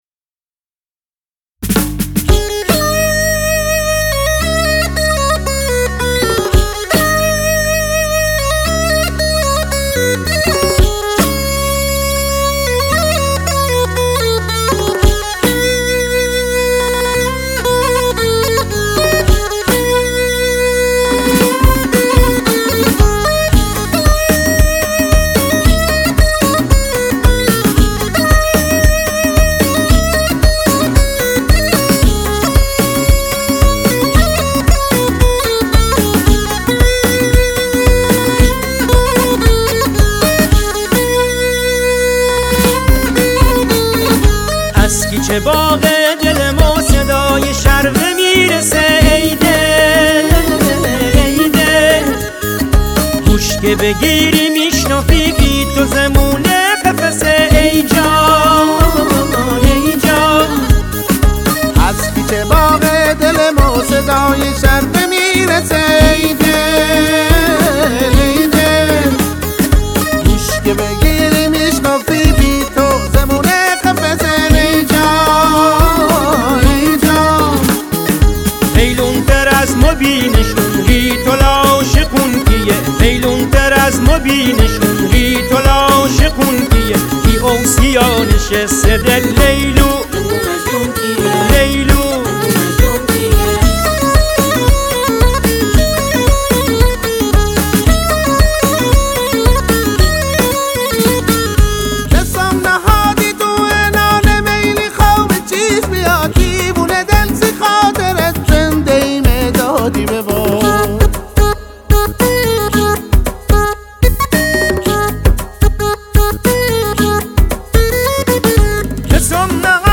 محلی
آهنگ با صدای زن